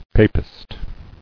[pa·pist]